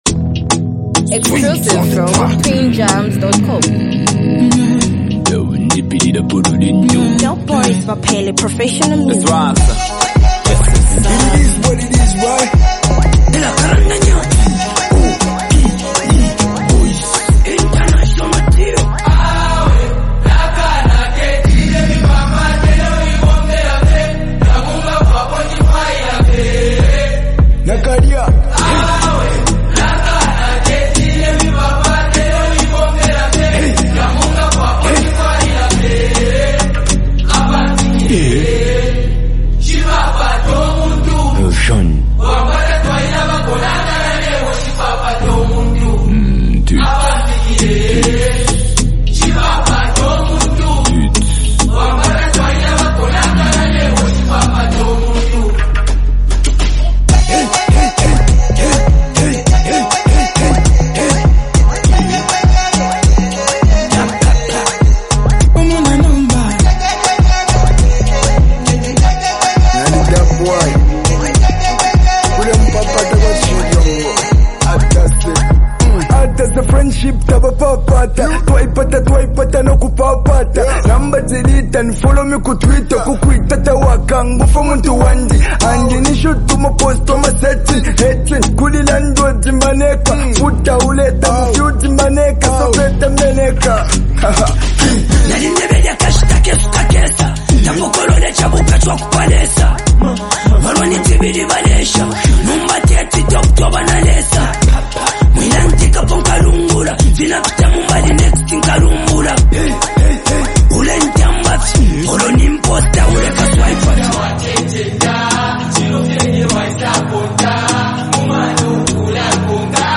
bold and confident song
He delivers his lines with a calm authority